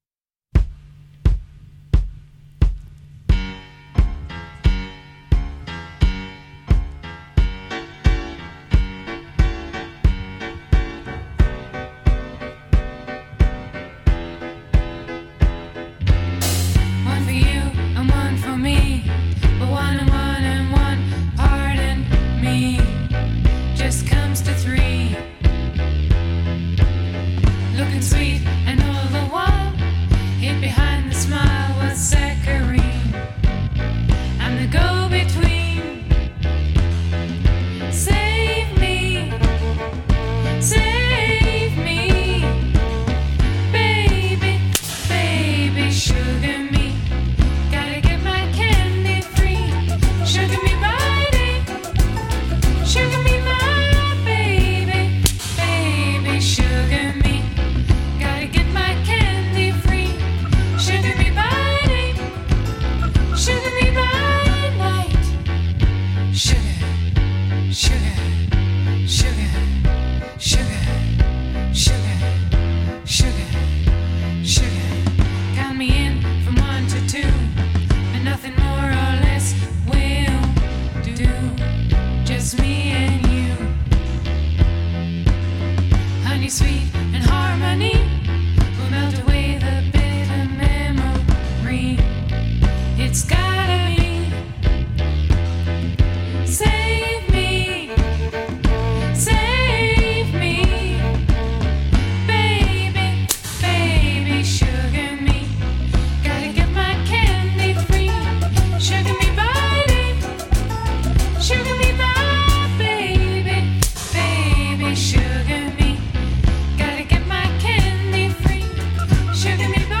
Какчество записи  да же лучше